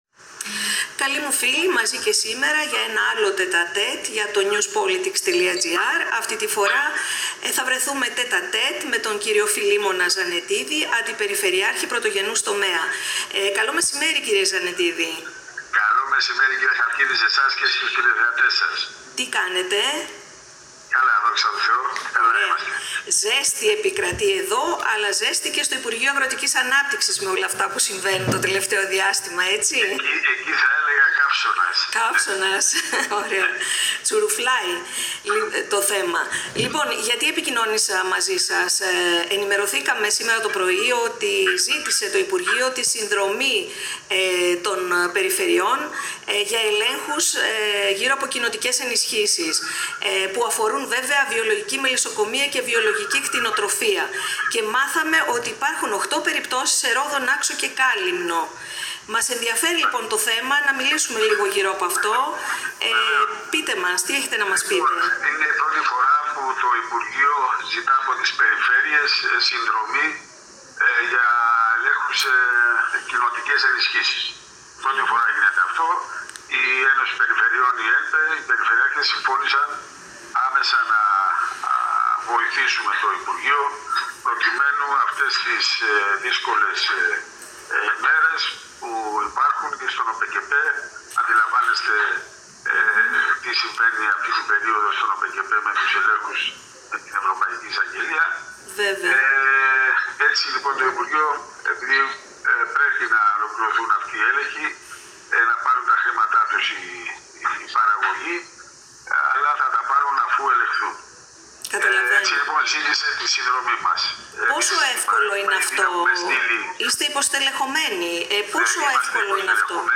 Συνδρομή της Περιφέρειας Νοτίου Αιγαίου στους Ελέγχους Βιολογικής Κτηνοτροφίας – Ο Φιλήμονας Ζαννετίδης, Αντιπεριφερειάρχης Πρωτογενούς Τομέα σε ένα ενδιαφέρον podcast
«Οι έλεγχοι γίνονται πάντα πριν τις ενισχύσεις, αλλά είναι η πρώτη φορά που ζητείται η συνδρομή των Περιφερειών. Μέχρι σήμερα γίνονταν αποκλειστικά από υπαλλήλους του Υπουργείου», ανέφερε ο κ. Ζαννετίδης σε podcast συνέντευξή του, σημειώνοντας πως ήδη απέστειλε κατάλογο υπαλλήλων που μπορούν να συνδράμουν, και οι έλεγχοι αναμένεται να ξεκινήσουν άμεσα, εντός της ερχόμενης εβδομάδας.